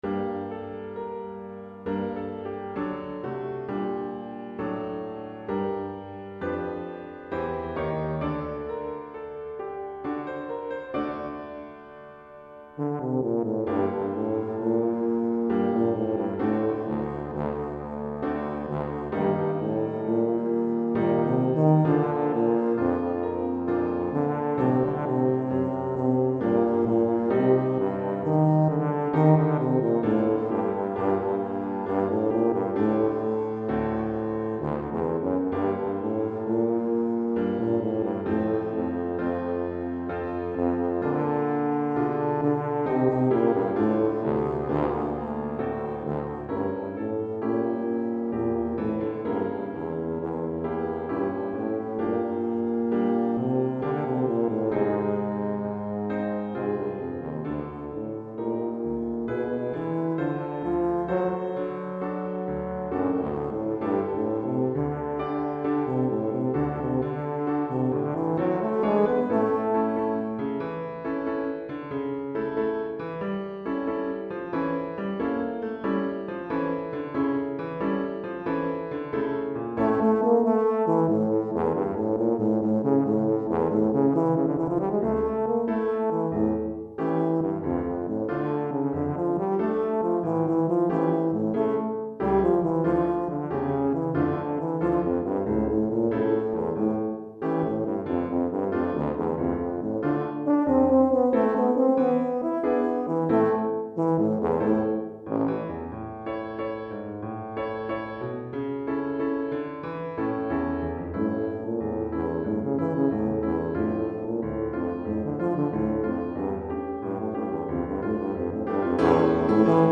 Tuba en Do et Piano